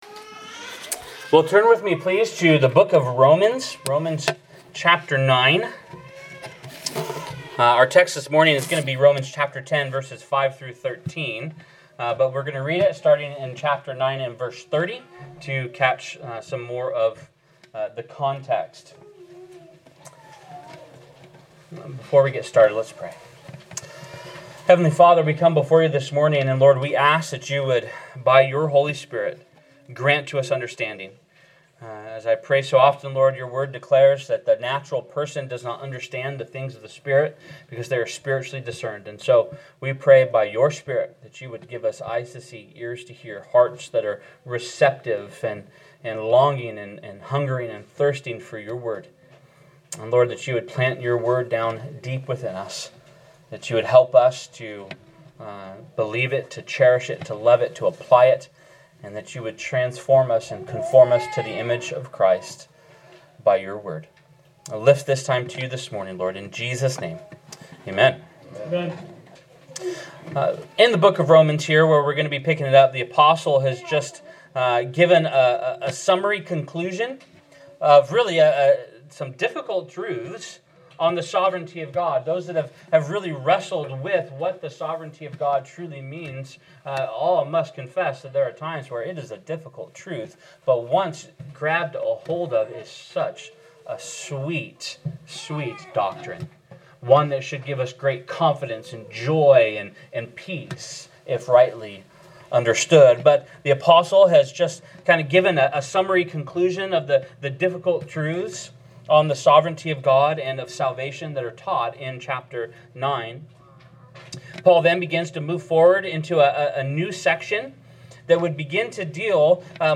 by Church Admin | Oct 1, 2023 | Sermons